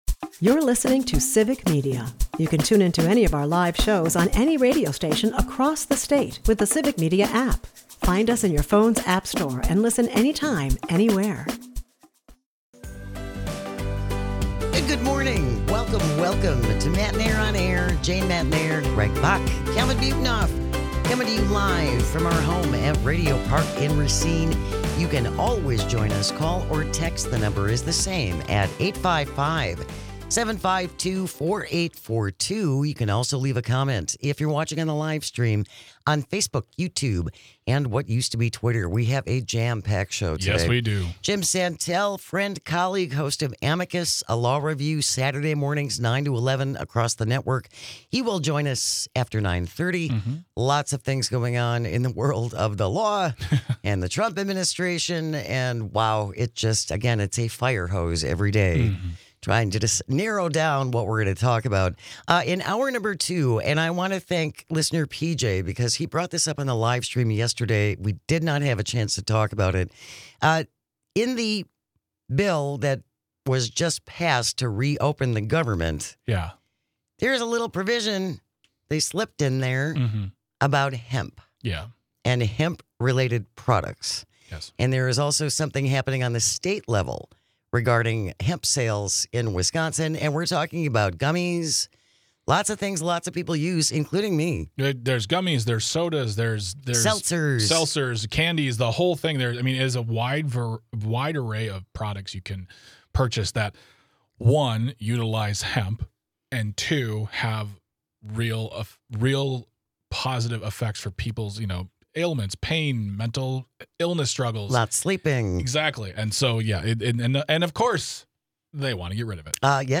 We The People, Are The Ones Who Pay (Hour 1) Guests: Jim Santelle
He is the host of Amicus: A Law Review and a former US Attorney. He breaks down all of the major stories coming out courts and world of law, including his thoughts on the potential to sue the government as well as new developments with The Epstein Files .